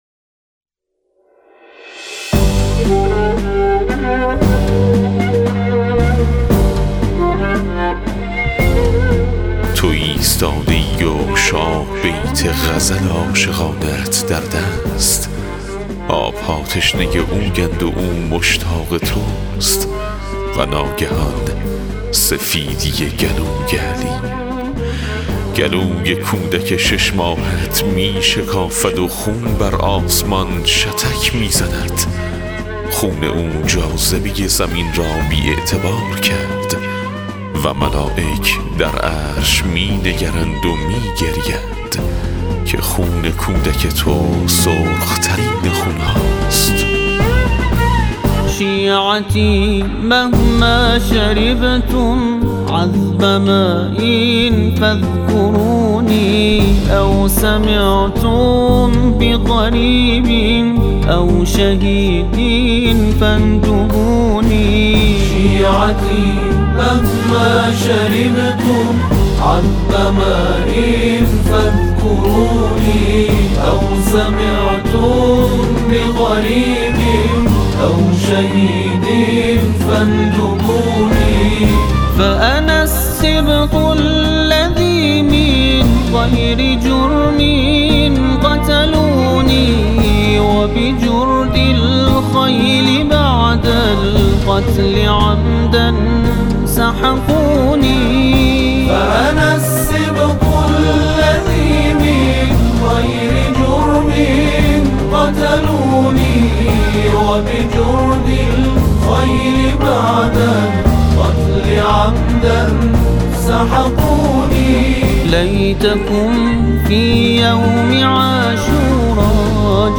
گوینده متن